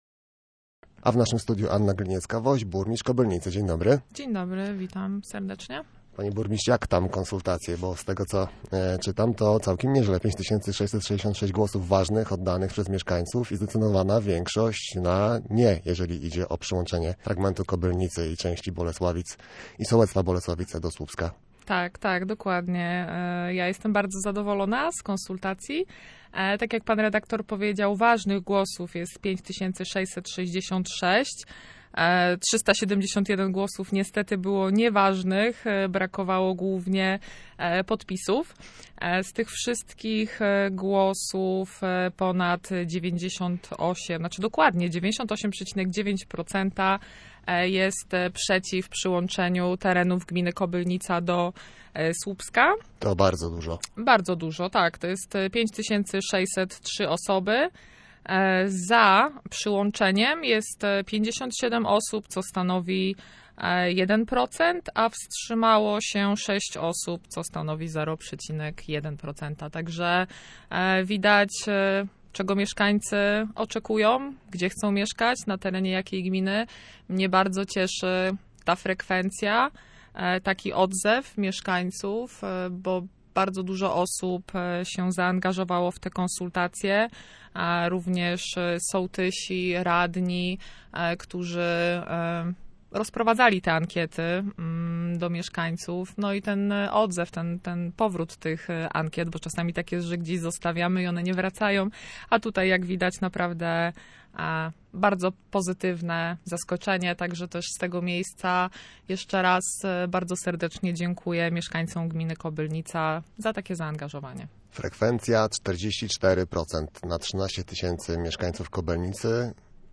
O konsultacjach, inwestycjach i relacjach ze Słupskiem rozmawiamy z burmistrz